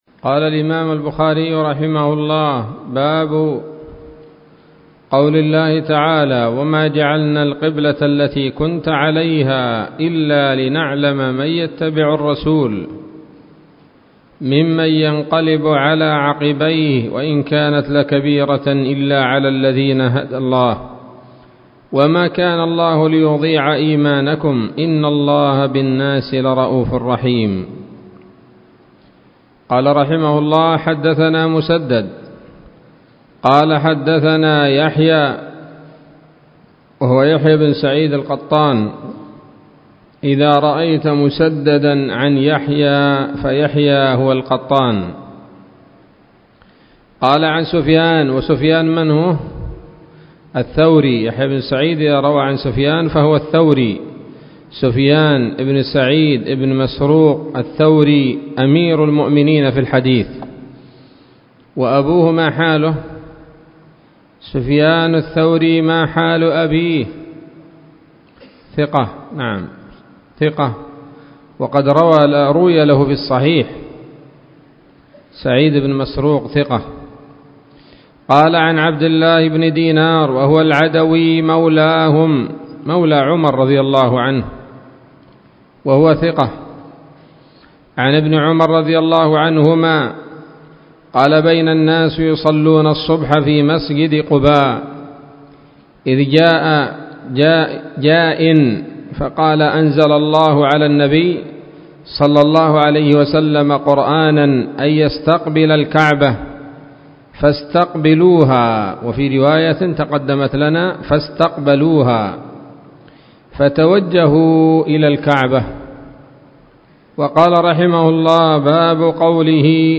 الدرس الخامس عشر من كتاب التفسير من صحيح الإمام البخاري